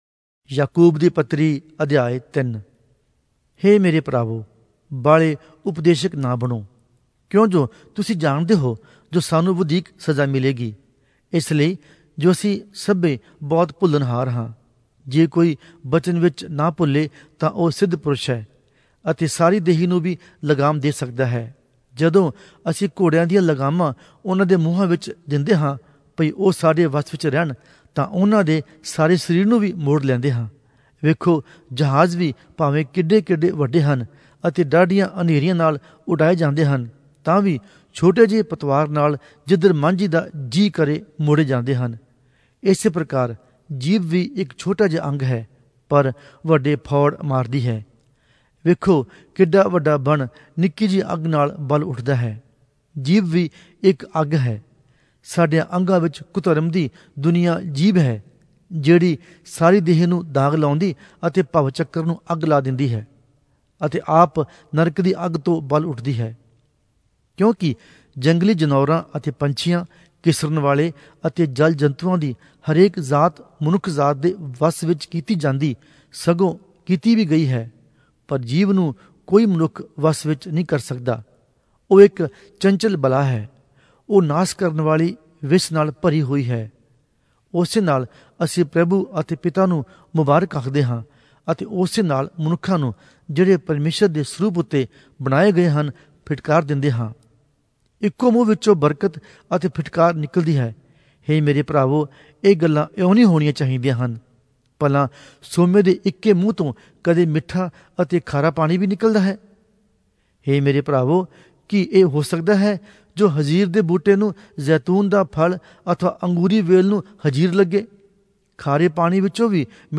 Oriya Audio Bible - James 2 in Hov bible version